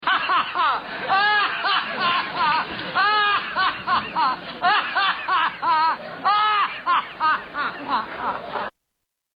Hilarious Laugh Larry Bud Melman Ren